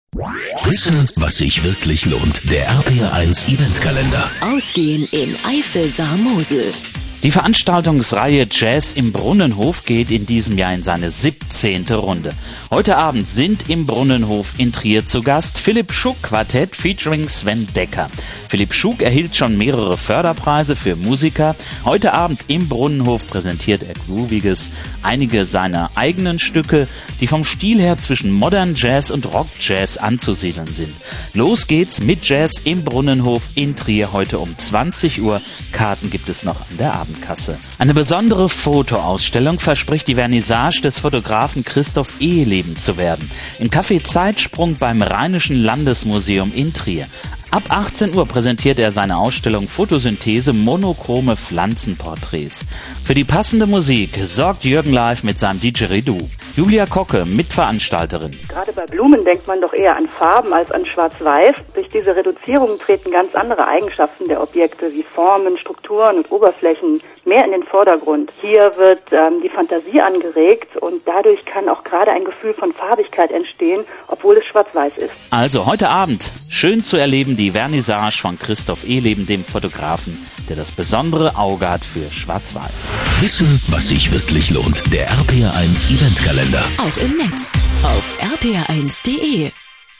Radio Ankündigung zur Vernissage Radio RPR1 vom 01.07.2010